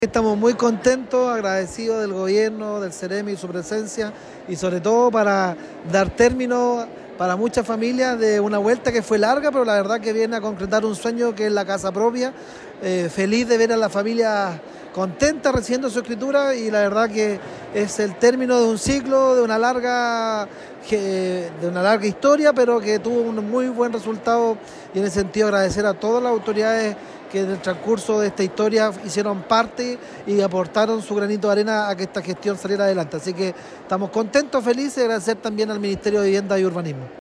En una emotiva ceremonia realizada en el gimnasio municipal de El Carmen, 209 familias recibieron las escrituras de sus viviendas, marcando el cierre de un largo proceso que se inició hace más de dos décadas.
Alcalde-de-El-Carmen-Renan-Cabezas.mp3